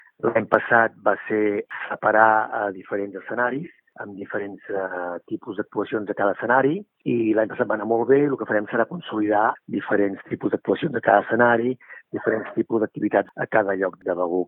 Eugeni Pibernat, regidor de Turisme de Begur.